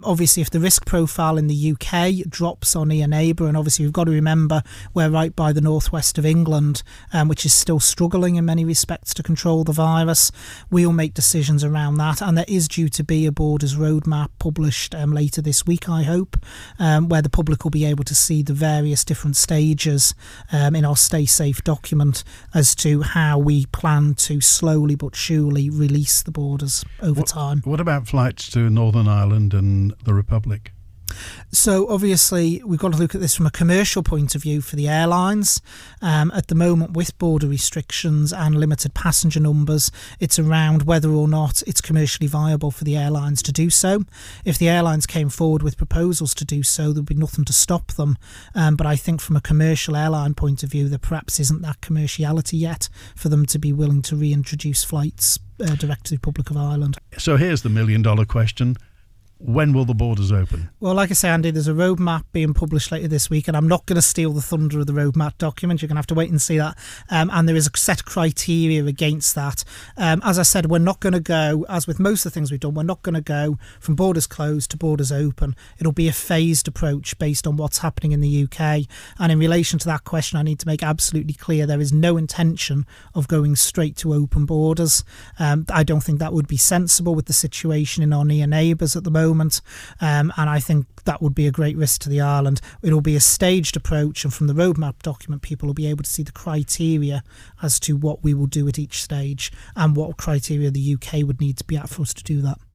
He was speaking live on the Mannin Line this lunchtime.